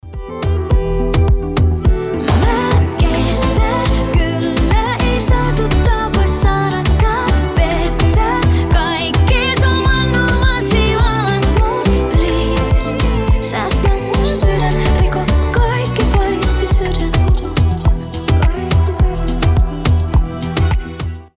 красивый женский голос